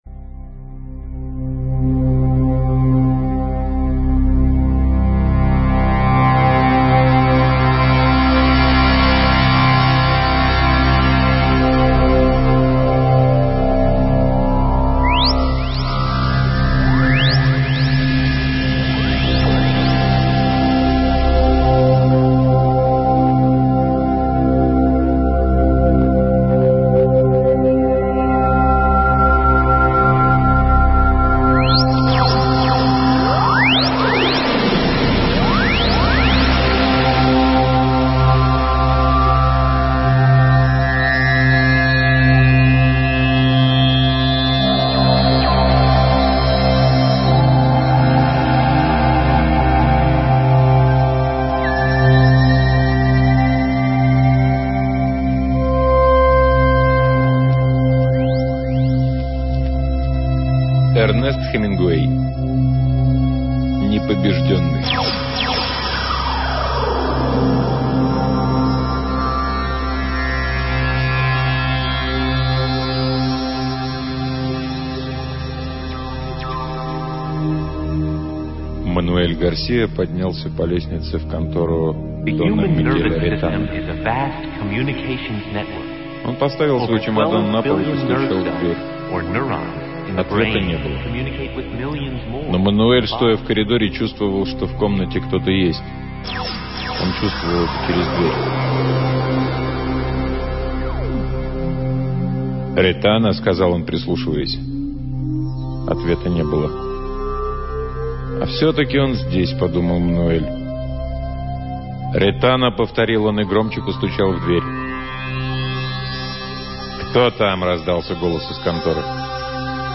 Название аудиокниги: Непобежденный Автор